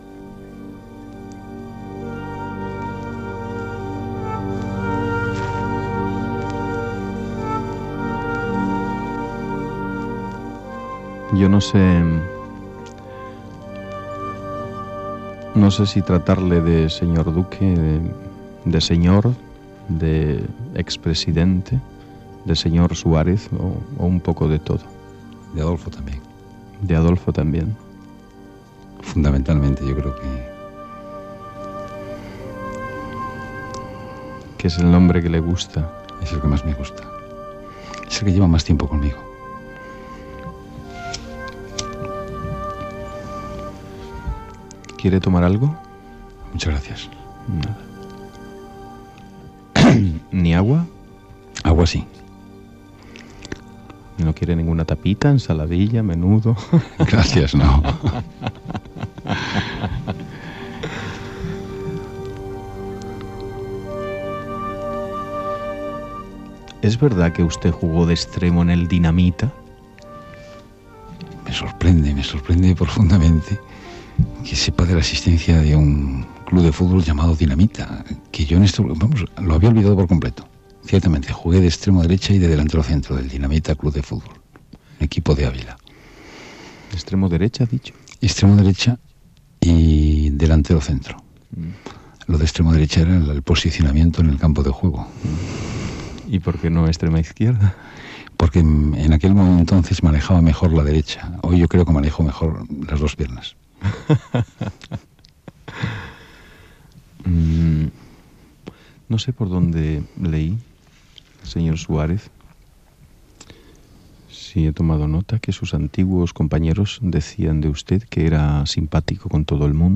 Fragment d'una entrevista a l'ex president del govern espanyol Adolfo Suárez.